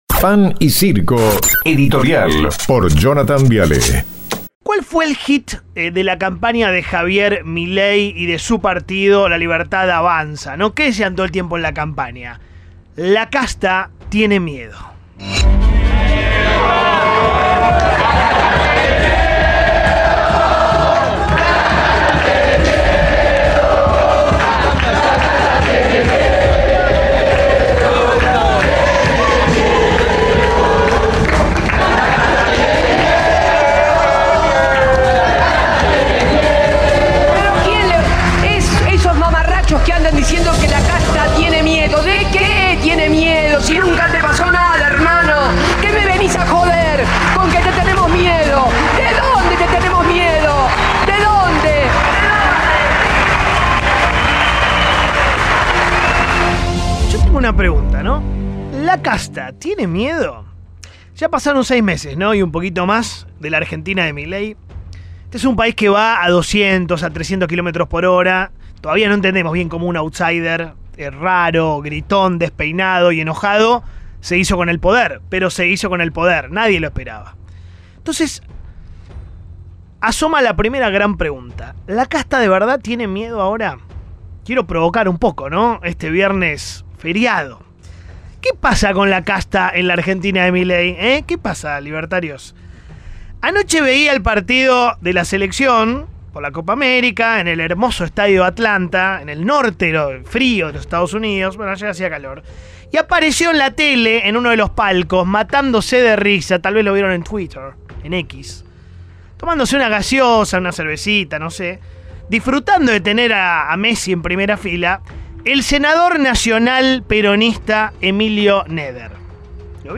El periodista hizo su reconocida editorial en su programa Pan y Circo por Radio Rivadavia.